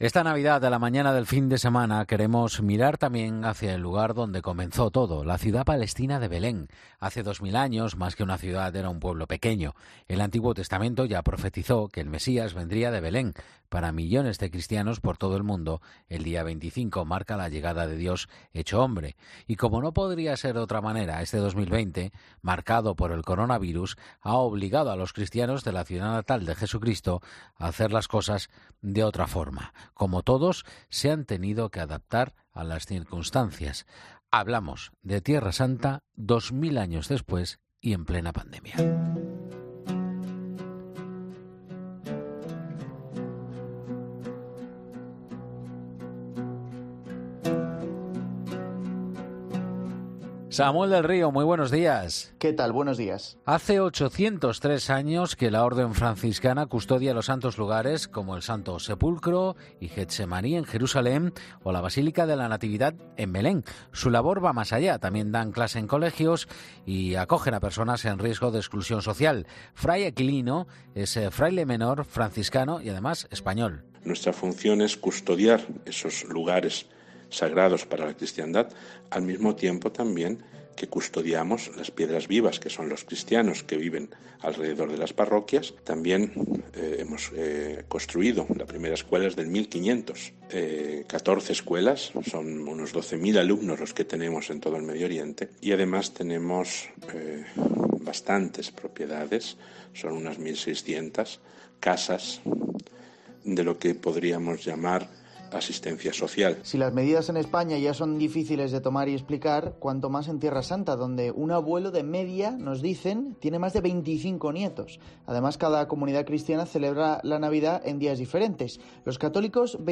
Nos ha contado cómo se está viviendo la Navidad en Belén.